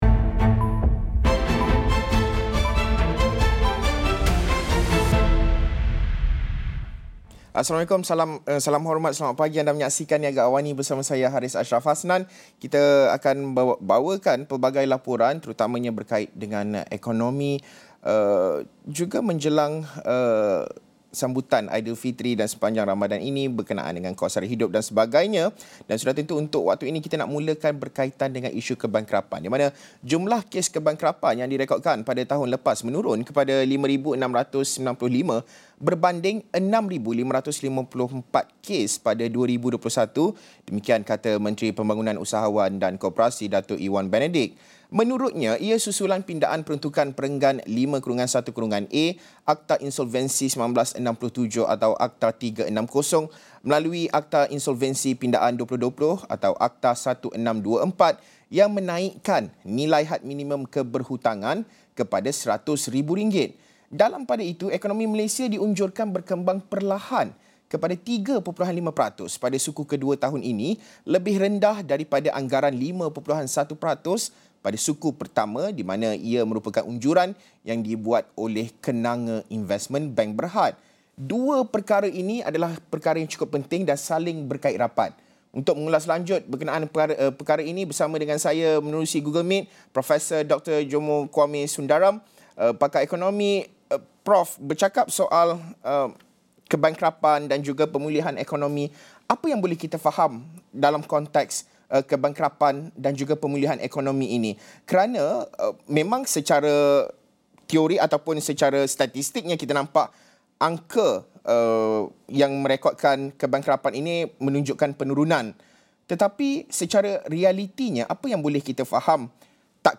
Pakar ekonomi, Prof Dr Jomo Kwame Sundaram berkongsi pandangan beliau berkenaan perkara ini.